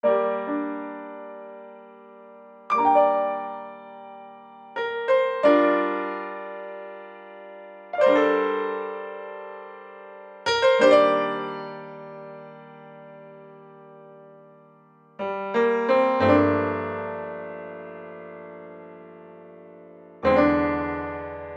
03 piano A.wav